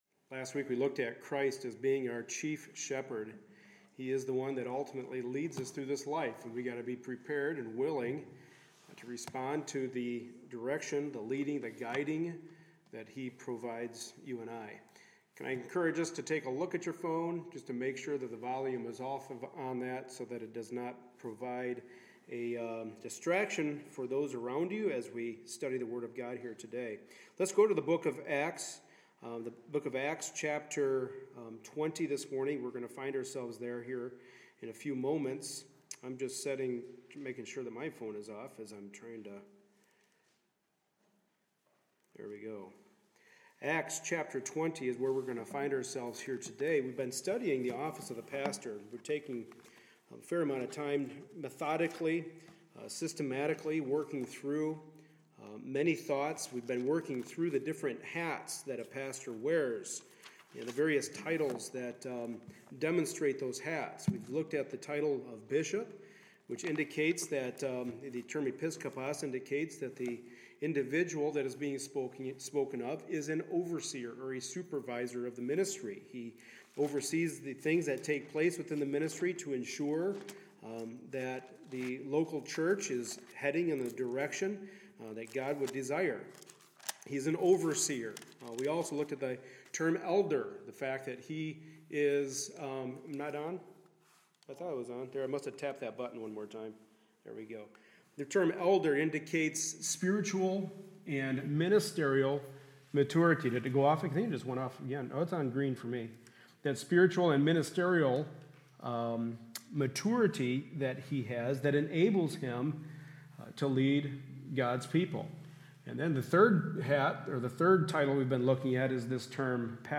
The Book of 1st Timothy Service Type: Sunday Morning Service A study in the pastoral epistles.